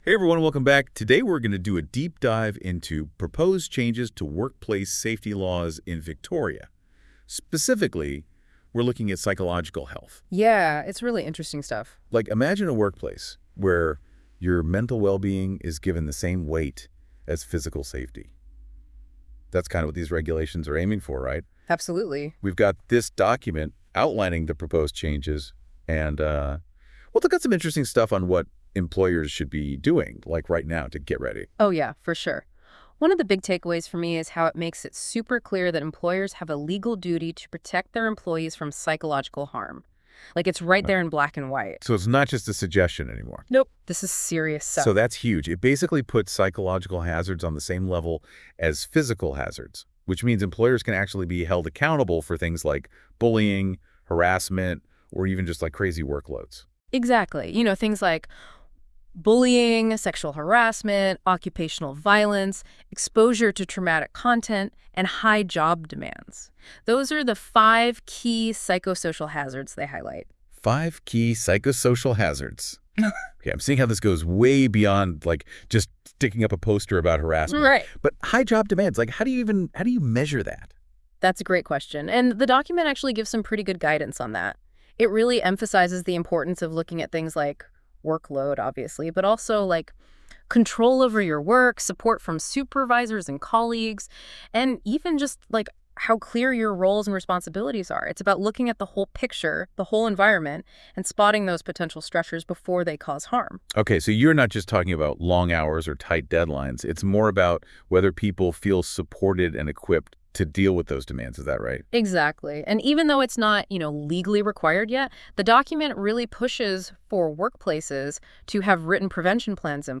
Here is a podcast on the proposed changes for those who enjoy the podcast format (this is AI generated).